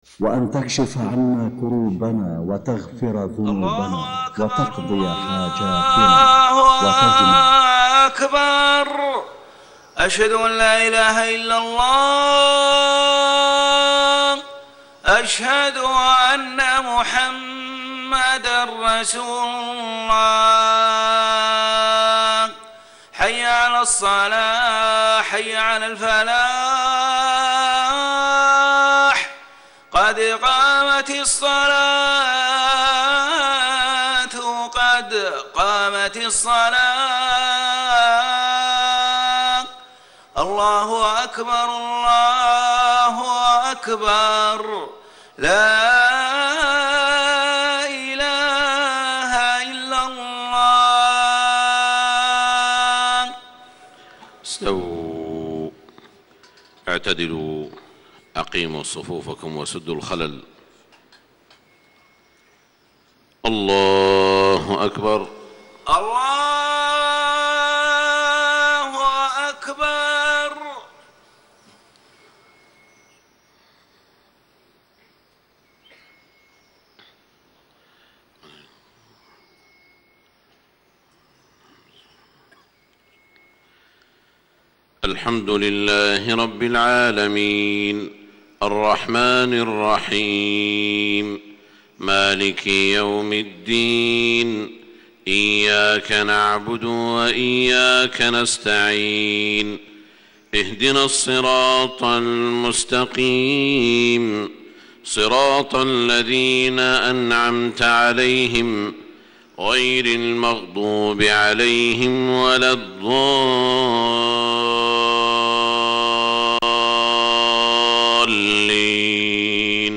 صلاة الفجر 4-5-1435 ما تيسرمن سورة الأنفال > 1435 🕋 > الفروض - تلاوات الحرمين